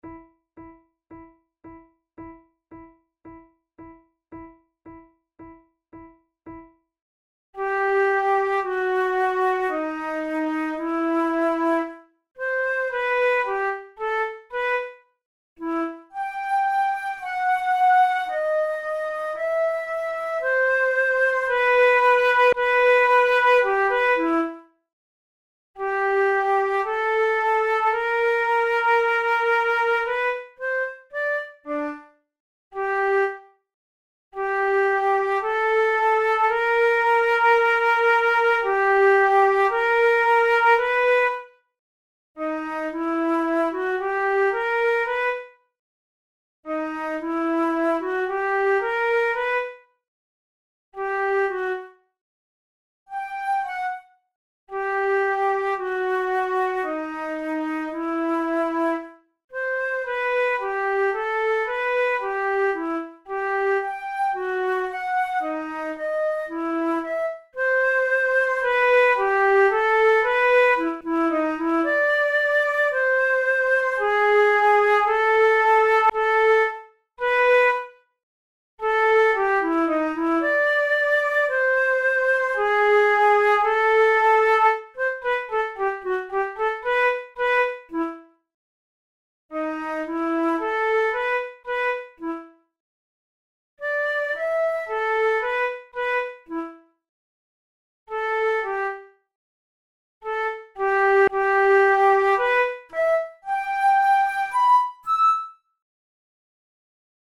This study for two flutes is taken from the celebrated Méthode de flûte by French flutist Jean-Louis Tulou, published in Paris in 1835.